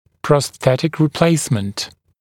[prɔs’θetɪk rɪ’pleɪsmənt][прос’сэтик ри’плэйсмэнт]замена на протез